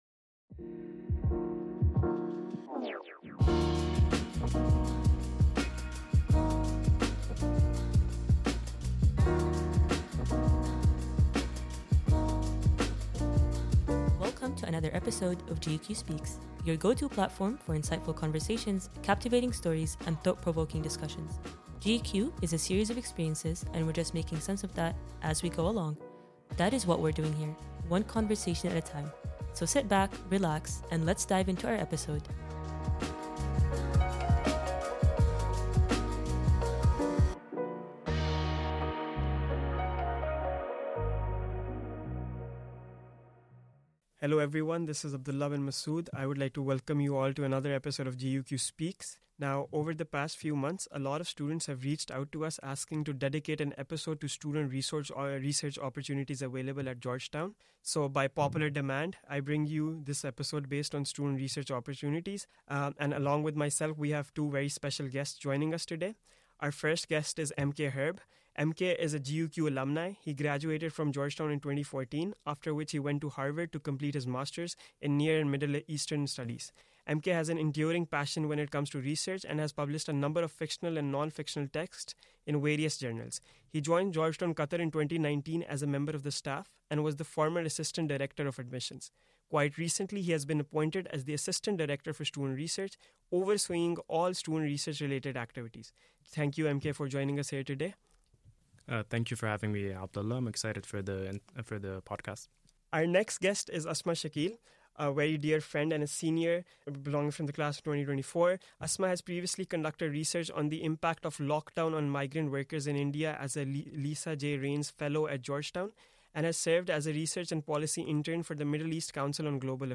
In this episode, podcast hosts and GU-Qatar alumni share insights and advice for undergraduate students embarking on research projects.